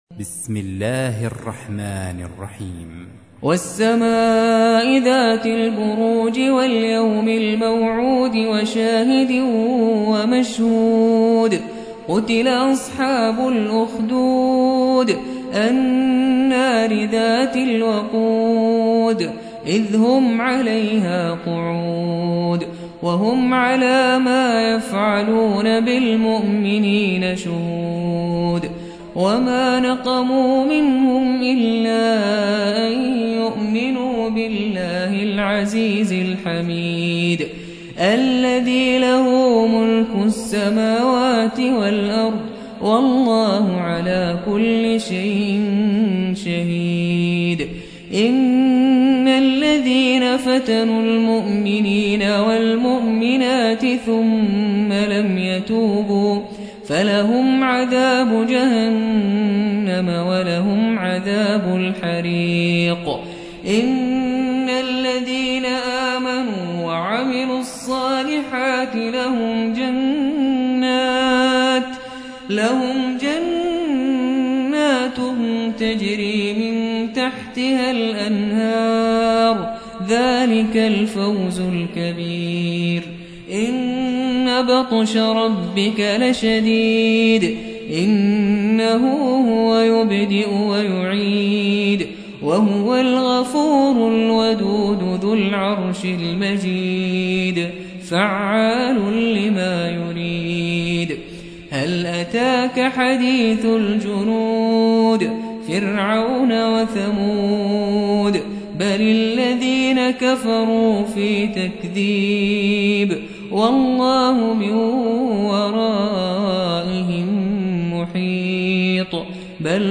تحميل : 85. سورة البروج / القارئ نبيل الرفاعي / القرآن الكريم / موقع يا حسين